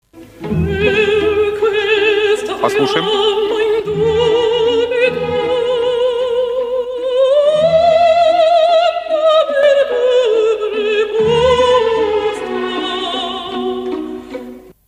Эта жена английского короля в одноимённой опере Доницетти поёт сопрано.